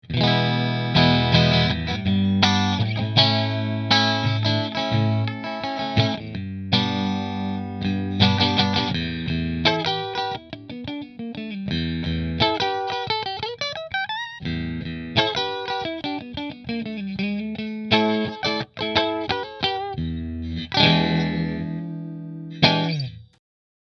• HSS Kent Armstrong Pickup Configuration
Rahan Guitars RP Double Cutaway Figured Maple Position 4 Through Marshall